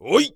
ZS瞬间起身1.wav
ZS瞬间起身1.wav 0:00.00 0:00.36 ZS瞬间起身1.wav WAV · 31 KB · 單聲道 (1ch) 下载文件 本站所有音效均采用 CC0 授权 ，可免费用于商业与个人项目，无需署名。
人声采集素材/男3战士型/ZS瞬间起身1.wav